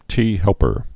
(tēhĕlpər)